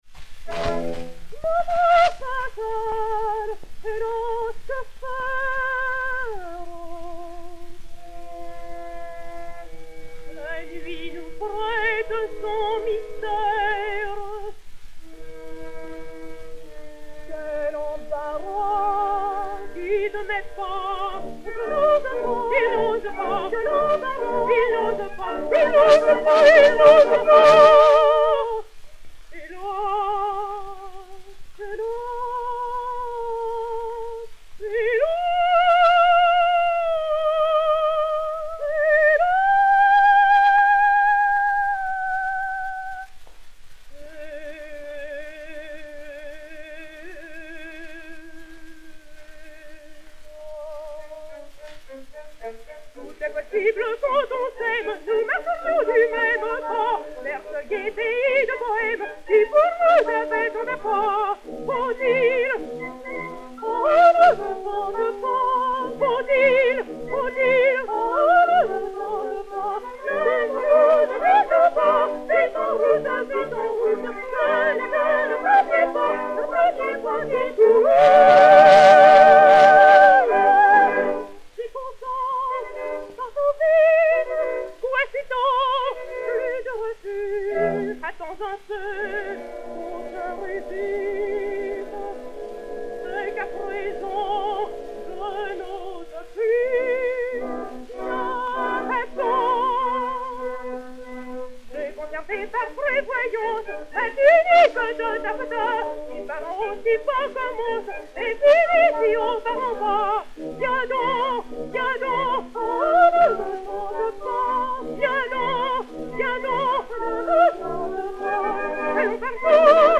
et Orchestre
Disque Pour Gramophone 34277, réédité sur P 299, mat. 16177u, enr. à Paris le 09 décembre 1910